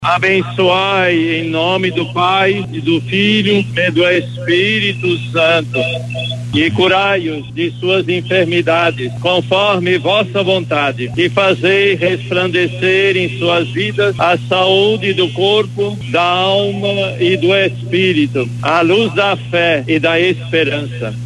Em frente ao Hospital Beneficente Portuguesa, o Arcebispo Metropolitano de Manaus, Cardeal Leonardo Steiner, realizou uma bênção especial aos enfermos, levando conforto espiritual a pacientes, familiares e profissionais de saúde que ali estavam.
SONORA-1-DOM-LEONARDO-.mp3